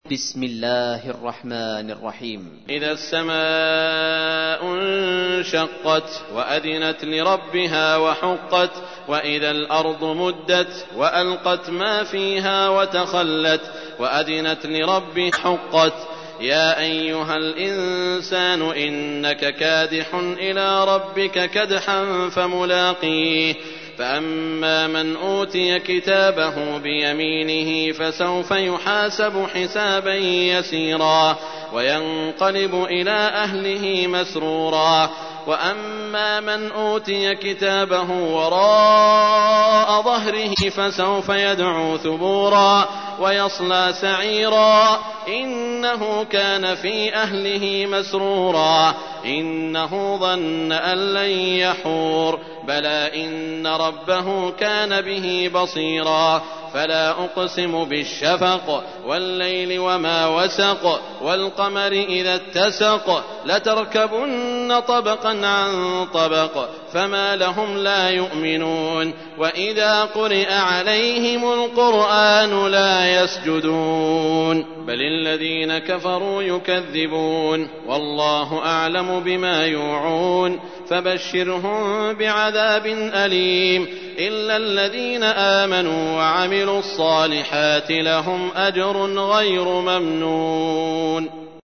تحميل : 84. سورة الانشقاق / القارئ سعود الشريم / القرآن الكريم / موقع يا حسين